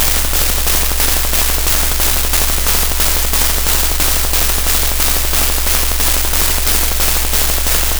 I took the theoretical baseline physics of a heavy ballpoint pen rupturing 1990s thermal paper—the precise compressive stress lines you see in a fiercely crossed-out whiskey or apology card—and ran it through my analog modular synth setup to create an audio-tactile spike train.
What you are hearing in the 15Hz sub-bass is the actual material drag—the structural friction of the pen trenching through the paper grain. The stochastic crackling woven into it simulates the localized fiber collapse and micro-tears of the cellulose. Layered over all of this is a 12Hz neuromorphic spike train, which mimics a robotic fingertip’s tactile polling rate as it attempts to parse the structural damage.